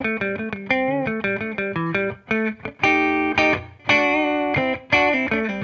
Index of /musicradar/sampled-funk-soul-samples/85bpm/Guitar
SSF_TeleGuitarProc2_85A.wav